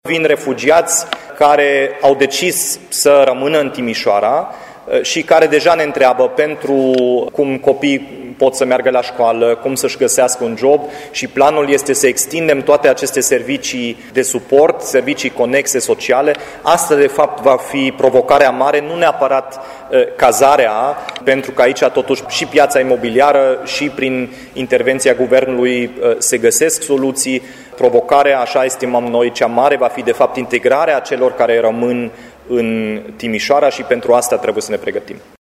Primarul Dominic Fritz dă asigurări că administrația locală le poate oferi refugiaților asistentă pentru găsirea unui loc de muncă.